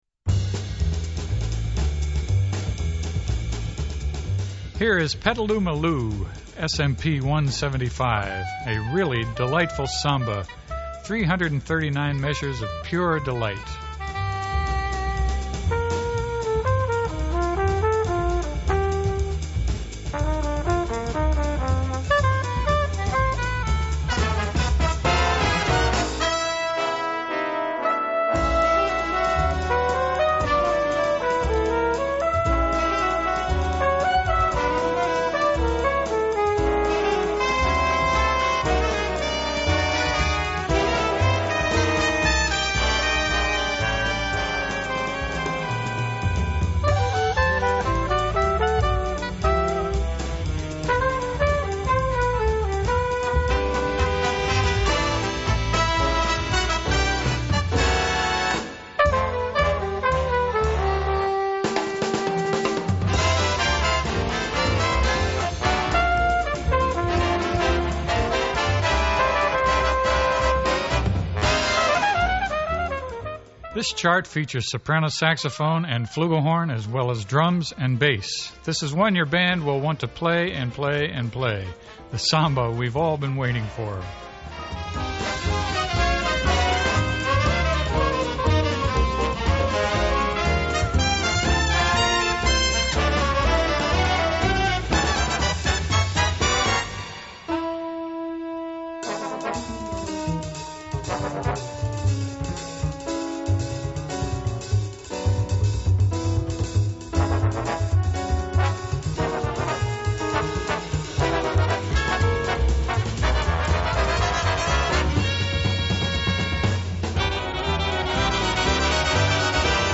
Guitar included.